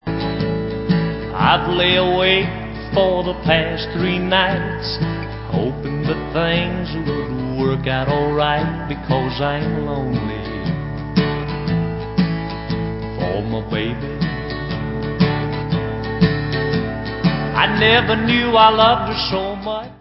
sledovat novinky v oddělení Rock & Roll